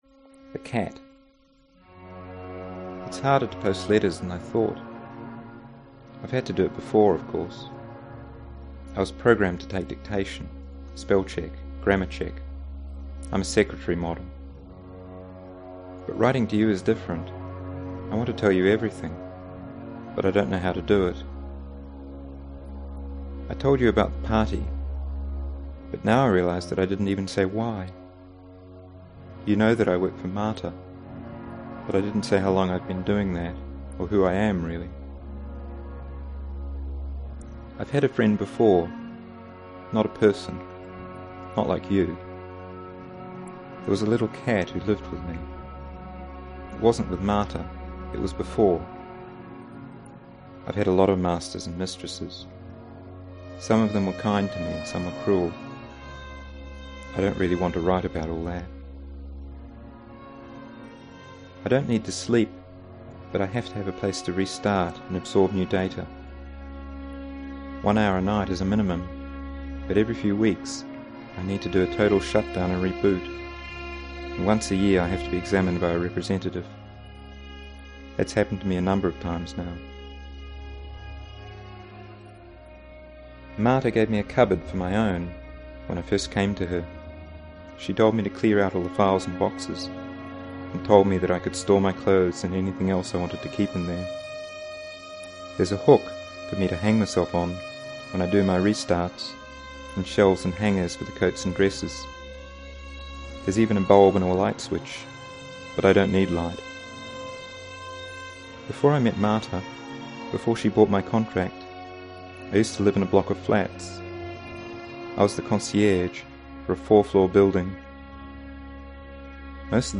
reading 'Cat' from EMO